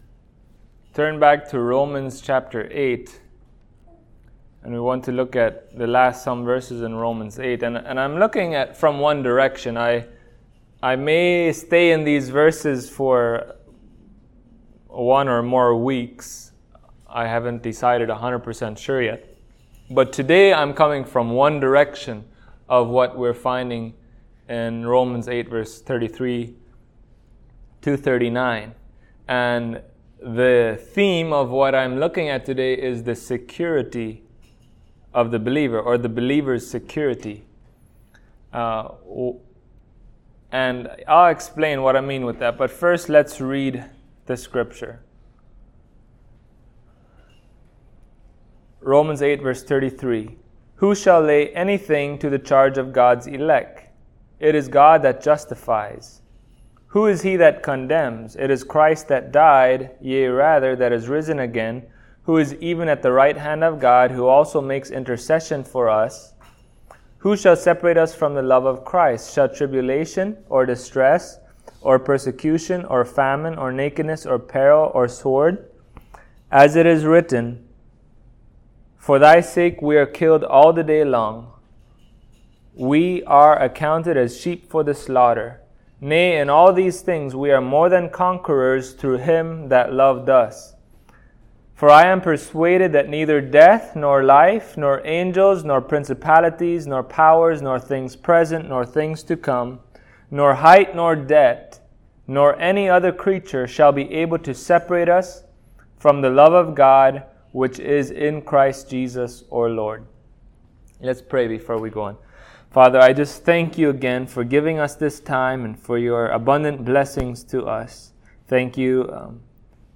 Service Type: Sunday Morning Topics: Perseverance of the Saints , Security « The Church The Truth